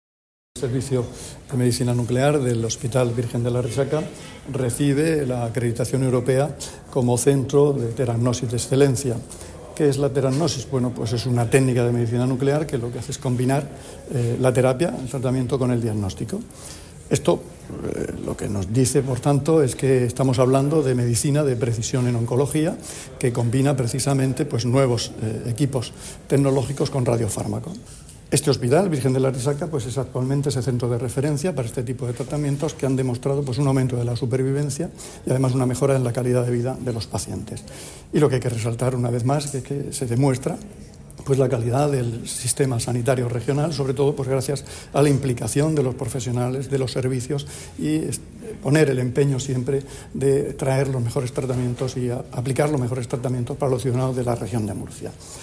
Declaraciones del consejero de Salud, Juan José Pedreño, sobre la acreditación del Servicio de Medicina Nuclear como Centro de Teragnosis de Excelencia.
El consejero de Salud, Juan José Pedreño, visitó el Servicio de Medicina Nuclear del hospital Virgen de la Arrixaca, donde se aplica la teragnosis, una técnica de precisión en Medicina Nuclear que fusiona terapia y diagnóstico.